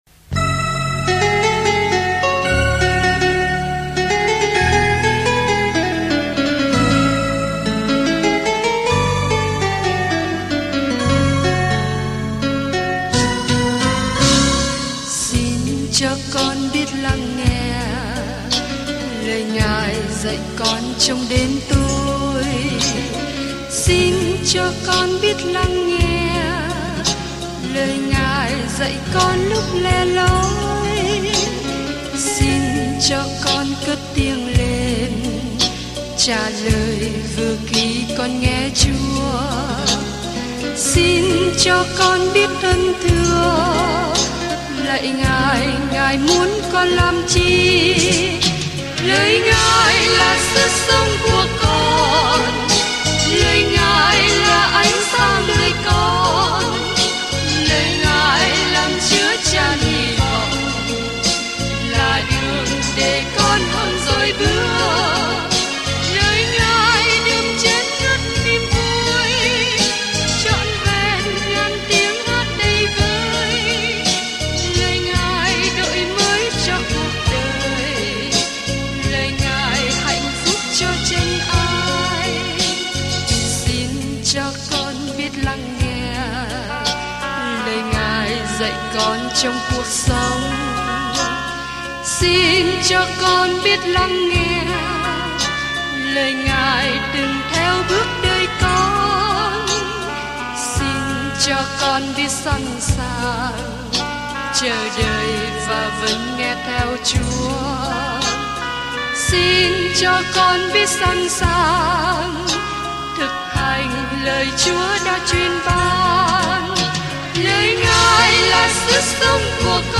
Kinh Thánh Lu-ca 21 Ngày 25 Bắt đầu Kế hoạch này Ngày 27 Thông tin về Kế hoạch Những người chứng kiến loan báo tin mừng Luca kể về câu chuyện Chúa Giêsu từ khi sinh ra cho đến khi chết cho đến khi phục sinh; Luca cũng kể lại những lời dạy của Ngài đã thay đổi thế giới. Du hành hàng ngày qua Luca khi bạn nghe nghiên cứu âm thanh và đọc những câu chọn lọc từ lời Chúa.